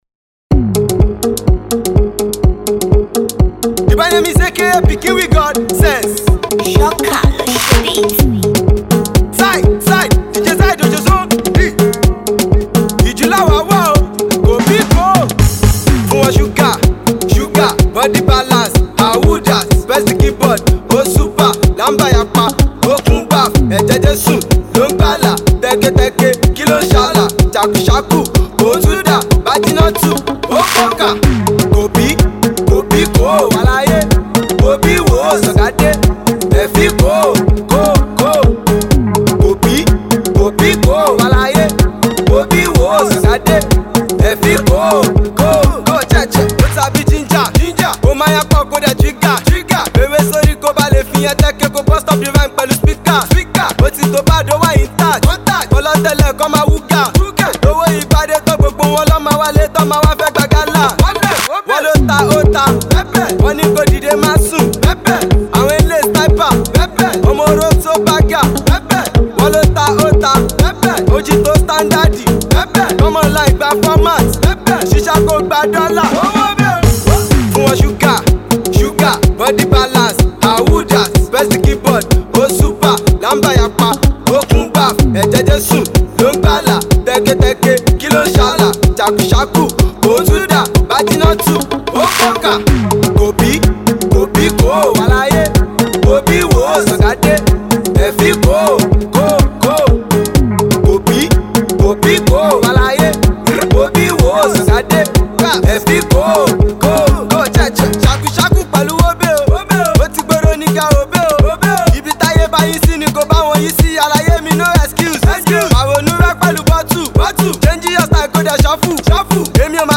street jam
dancehall jam